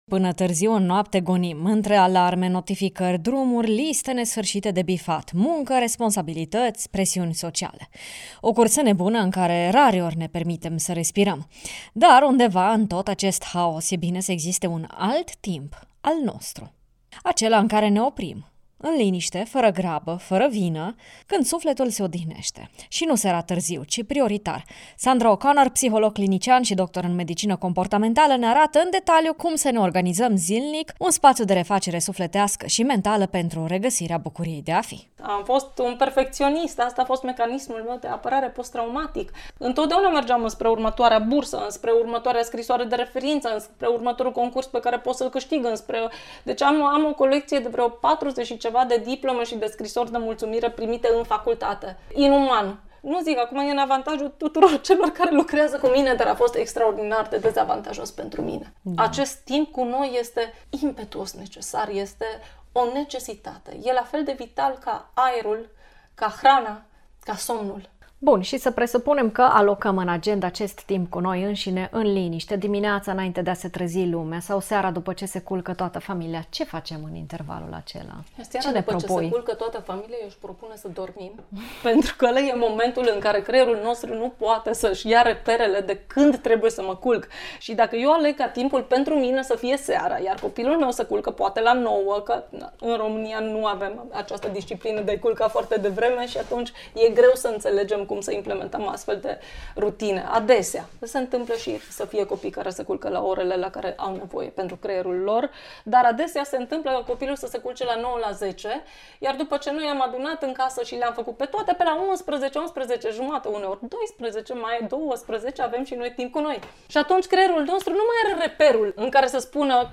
psiholog clinician